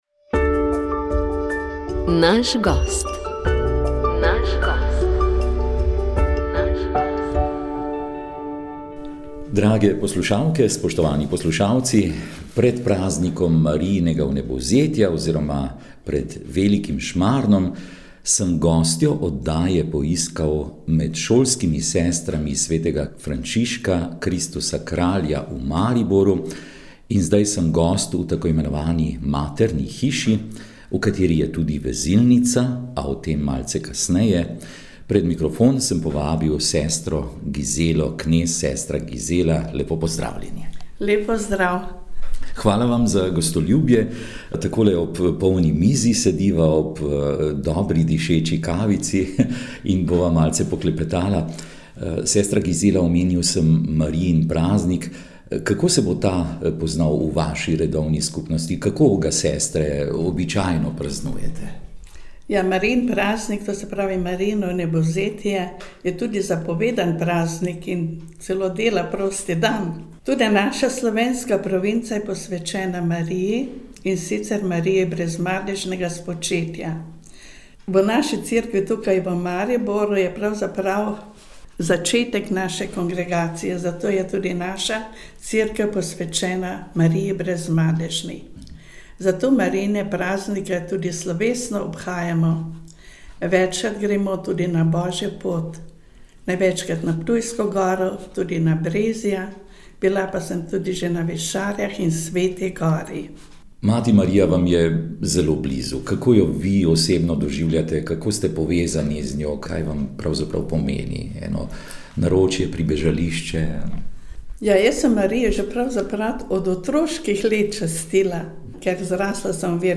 pogovor